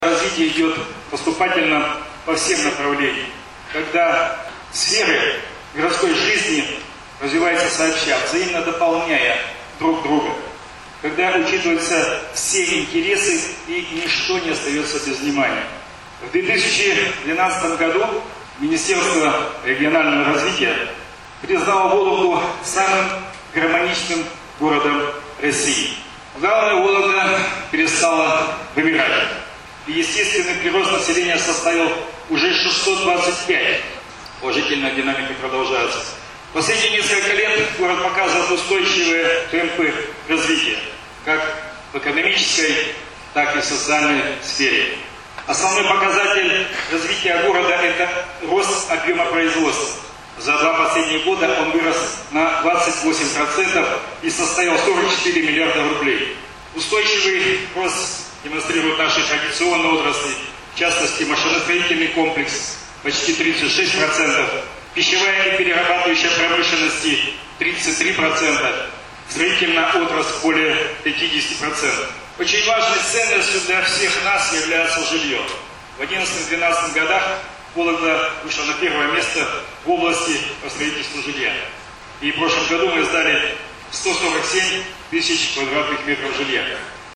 Евгений Шулепов отчитался перед жителями областной столицы об основных итогах социально-экономического развития Вологды.
Евгений Шулепов рассказывает о развитии Вологды в прошлом году
Отчет заслушали несколько сотен вологжан